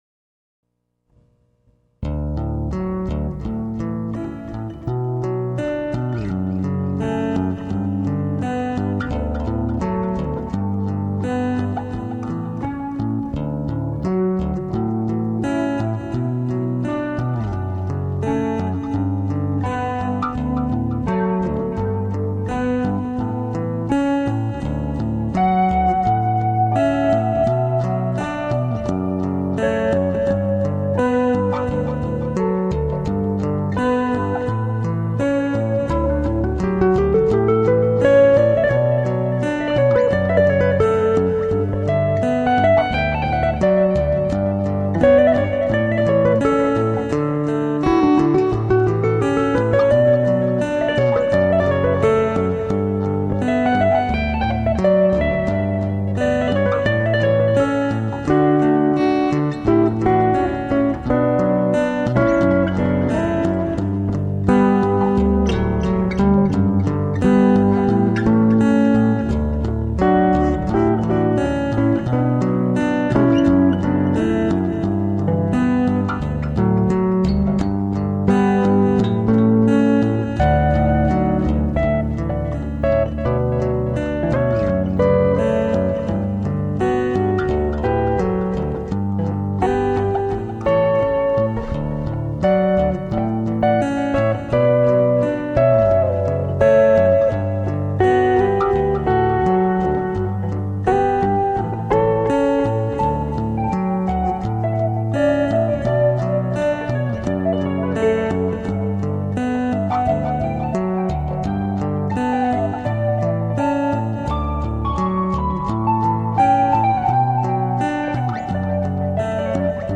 Chapman Stick instrumentalist.